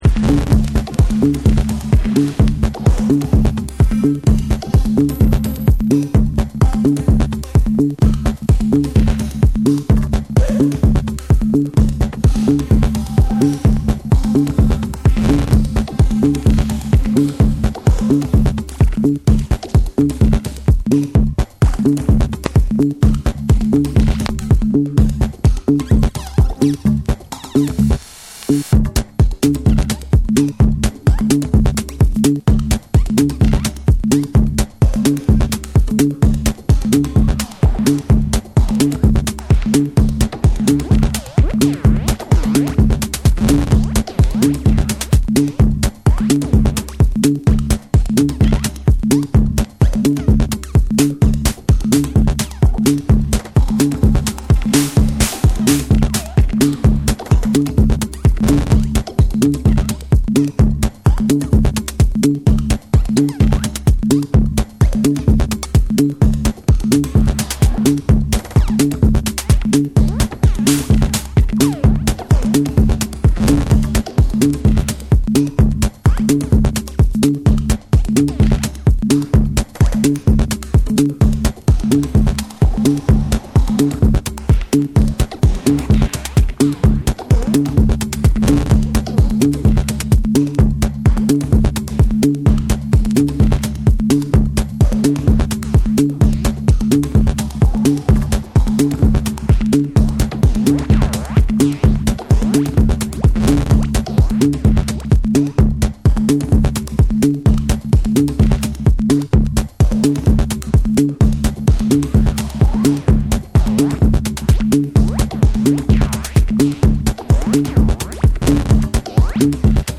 静謐な美しさをたたえつつ、緻密なパーカッションがフロアを引き込む
TECHNO & HOUSE / ORGANIC GROOVE